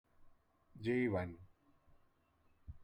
En las páginas o libros en inglés, esta palabra es transliterada como jeevan o jivan.
En español el sonido equivalente a es ll. Llivan.